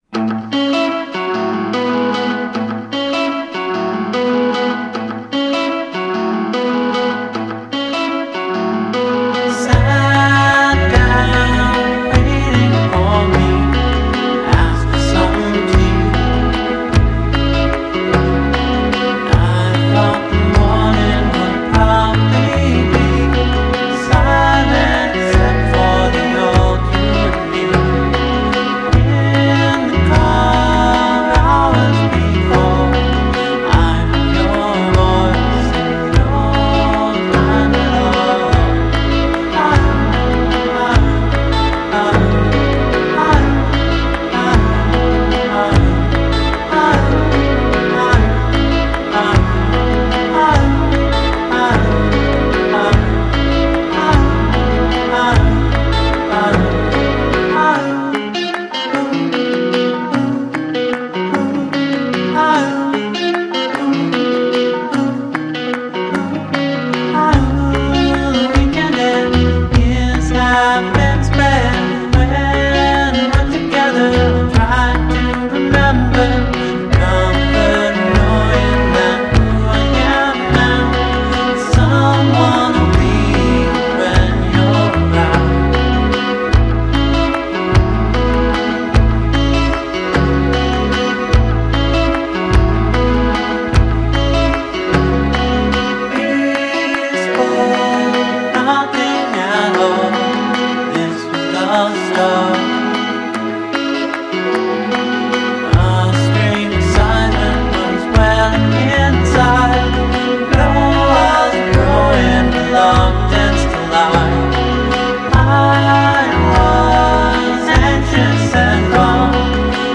psychedelic art-pop band